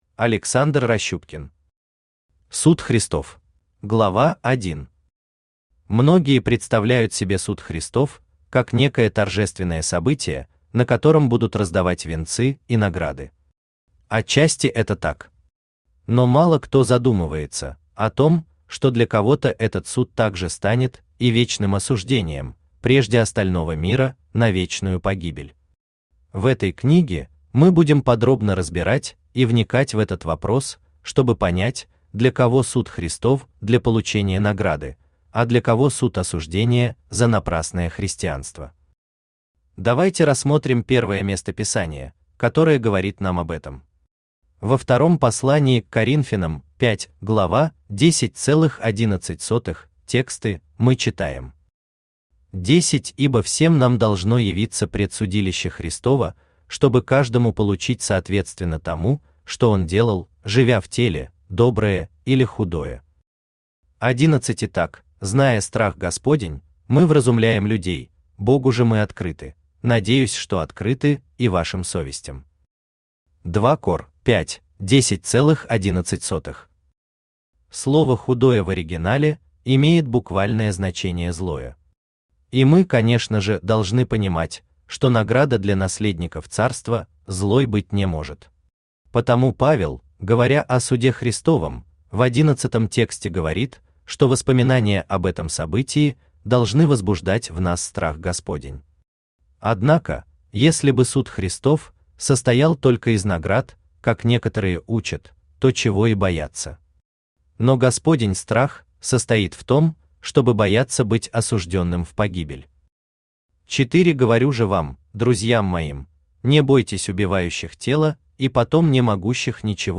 Аудиокнига Суд Христов | Библиотека аудиокниг
Aудиокнига Суд Христов Автор Александр Сергеевич Ращупкин Читает аудиокнигу Авточтец ЛитРес.